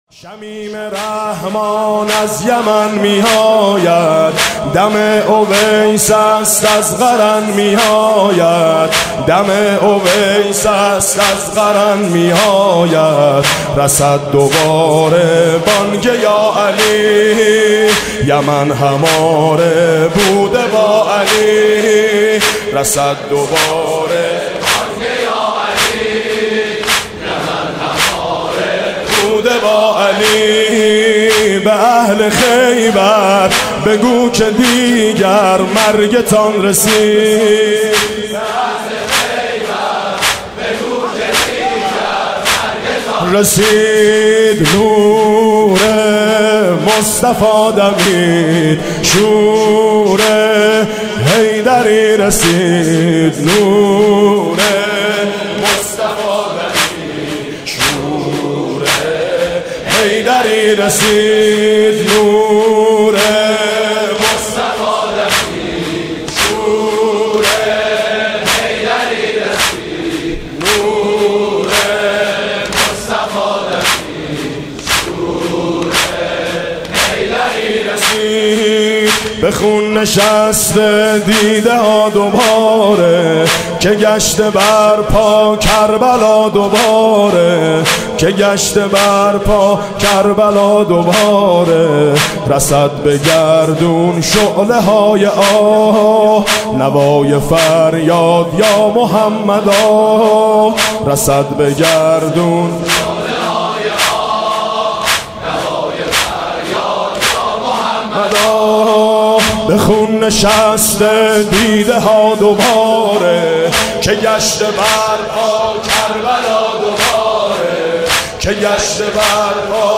مداحی فارسی و عربی